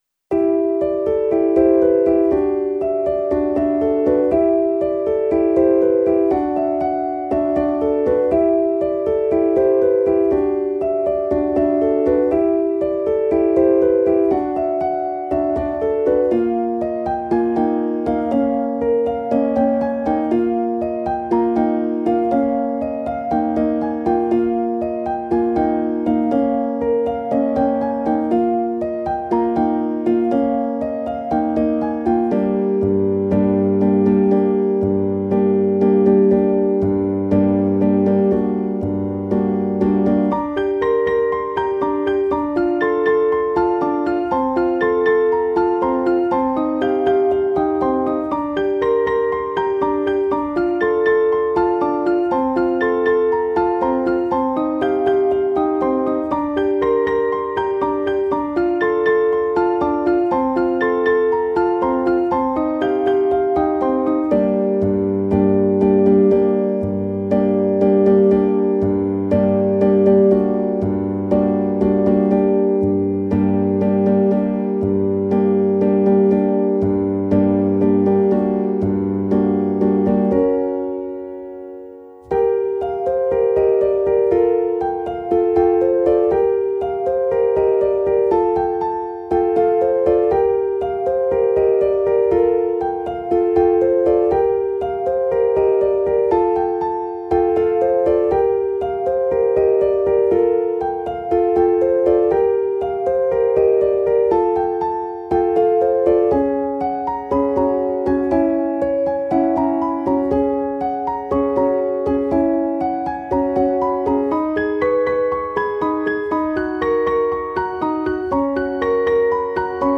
PIANO ABC (33)